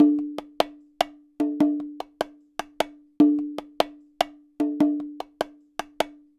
Bongo 03.wav